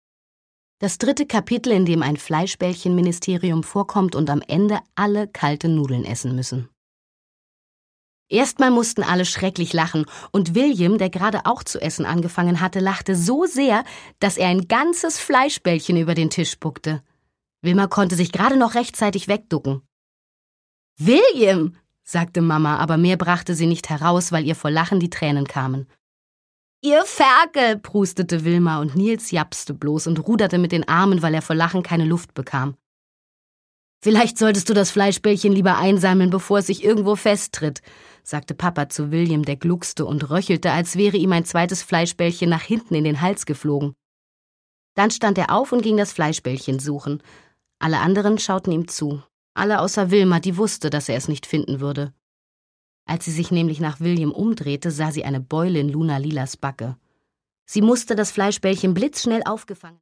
Produkttyp: Hörbuch-Download
Fassung: Autorisierte Lesefassung